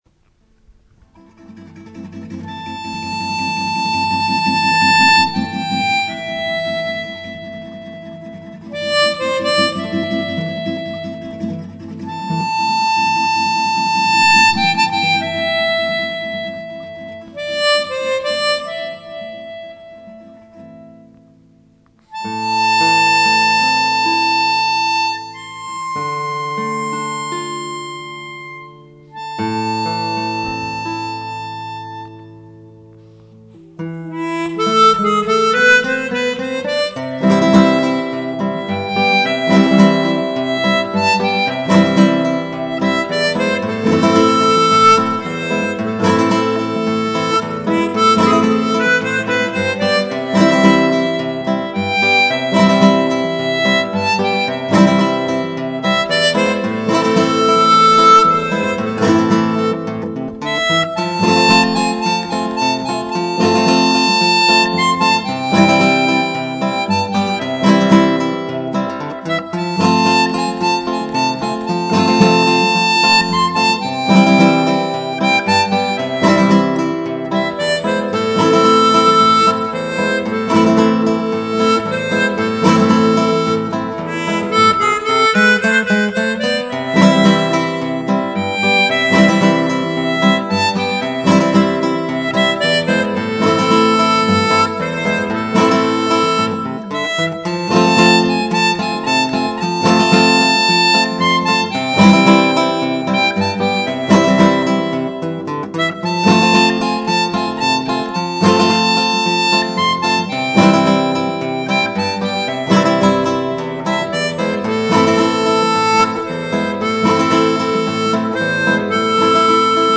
On est transporté sur les plateaux andins
Chouette play back aussi!